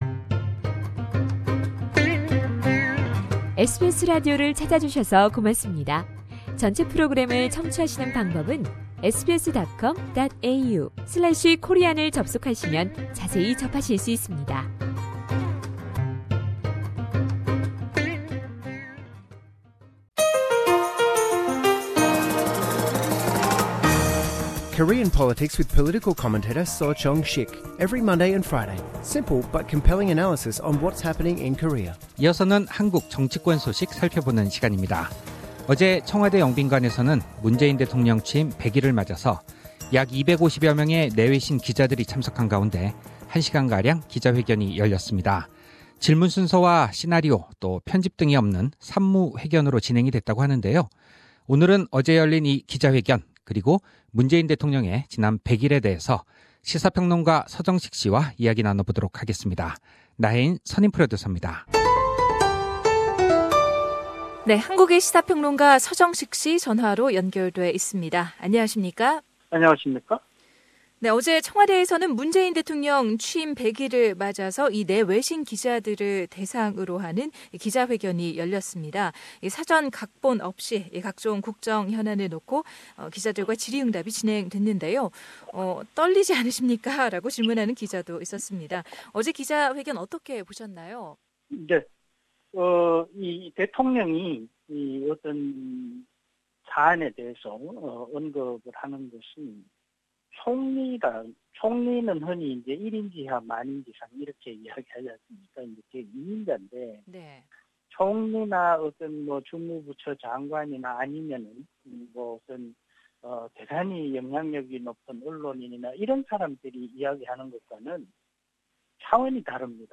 상단의 팟캐스트를 통해 전체 인터뷰를 들으실 수 있습니다.